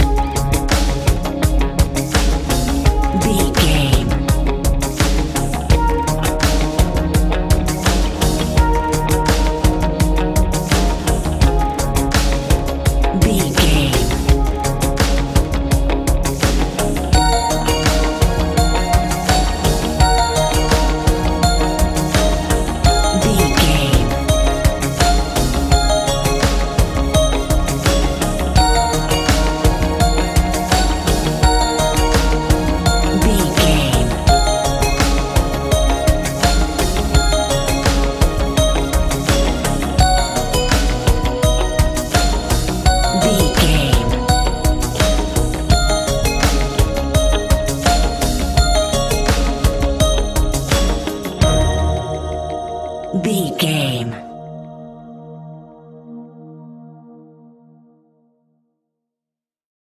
Ionian/Major
C♭
electronic
techno
trance
synths
synthwave
instrumentals